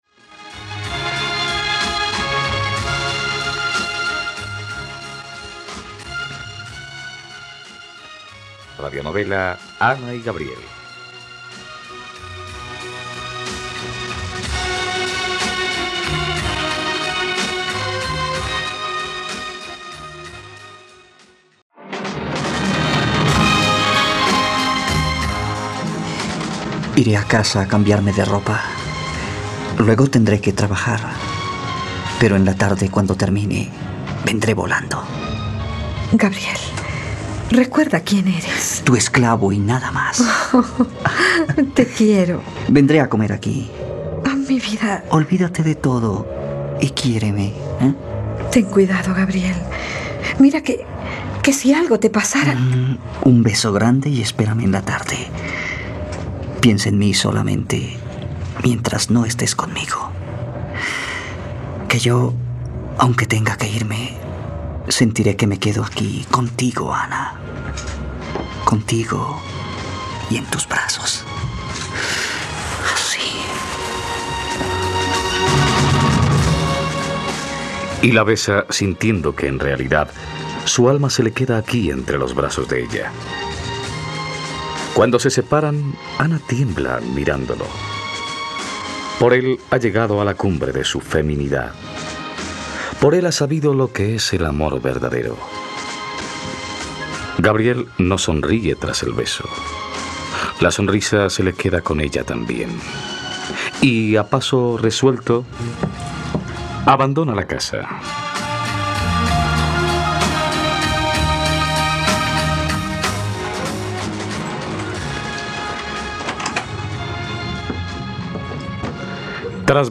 Ana y Gabriel - Radionovela, capítulo 81 | RTVCPlay